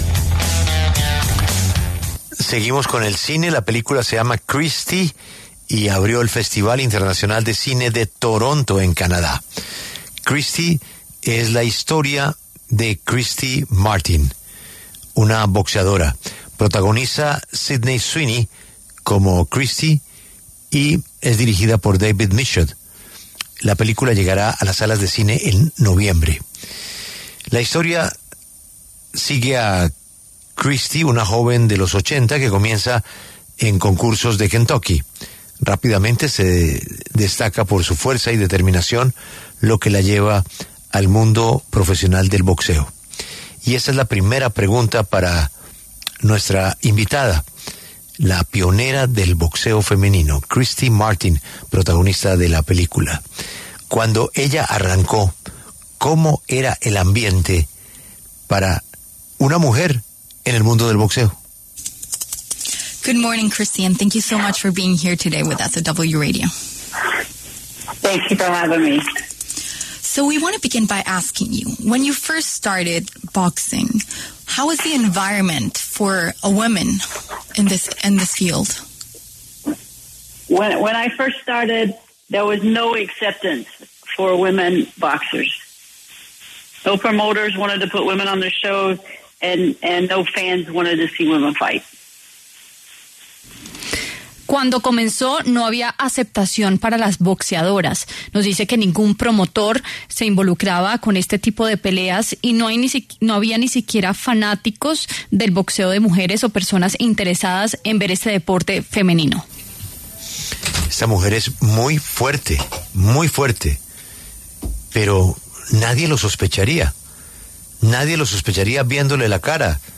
Christy Martin, pionera del boxeo femenino, pasó por los micrófonos de La W para hablar sobre su película biográfica y reveló algunos detalles de su vida personal.